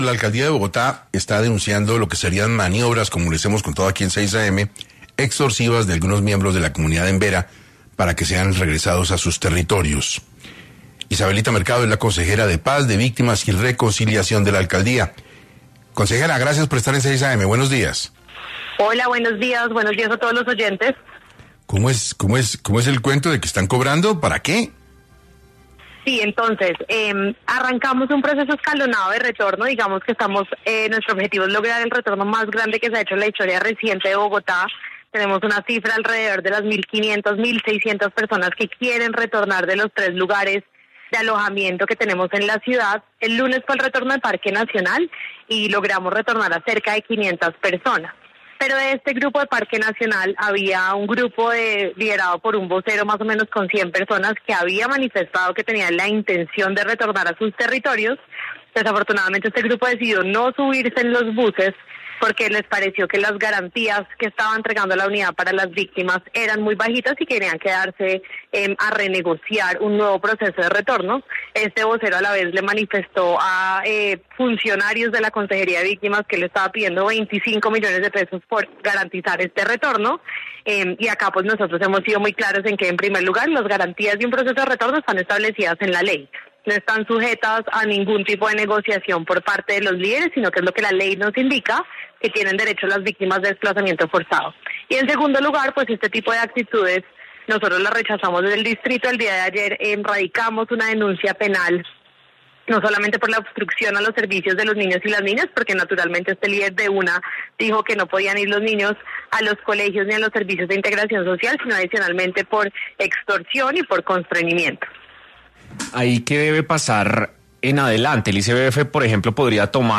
Isabelita Mercado, consejera de Paz, Víctimas y Reconciliación de la Alcaldía de Bogotá, pasó por 6AM para hablar del retorno masivo de familias Embera a sus territorios de origen.